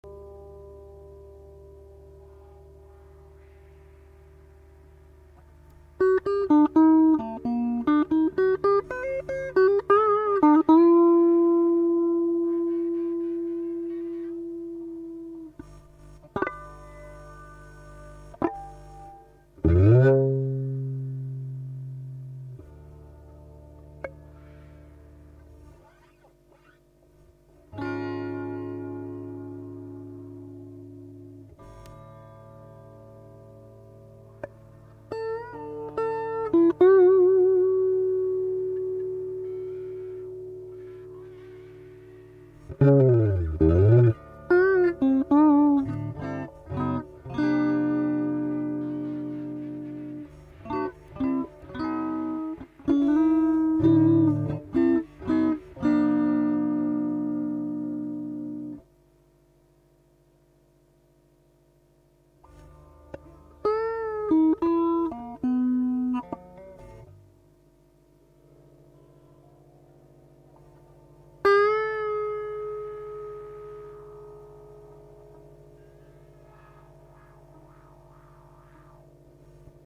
木っ端Wahペダル組み合わせてもいけそうゃて。